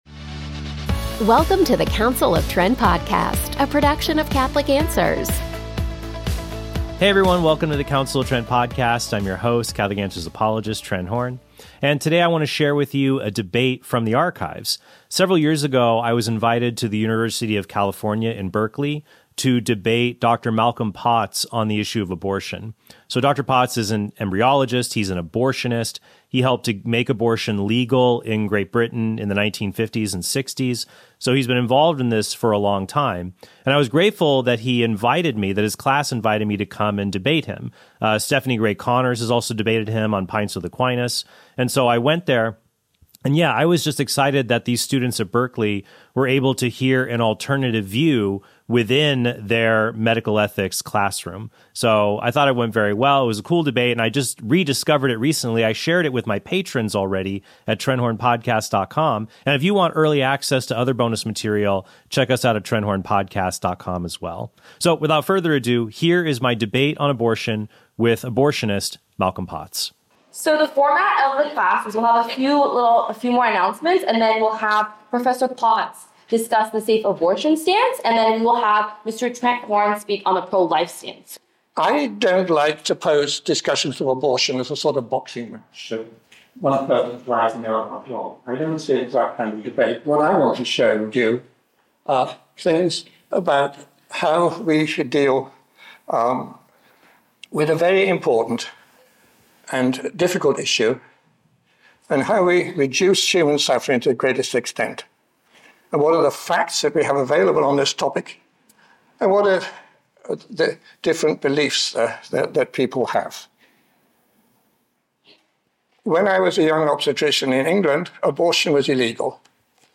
DEBATE: Should Abortion Be Legal?